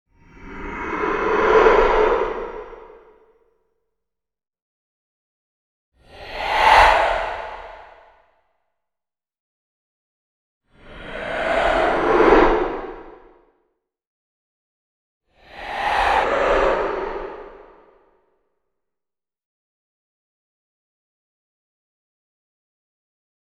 Demon Breath Sound
horror